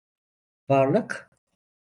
Προφέρεται ως (IPA)
/vɑɾˈɫɯk/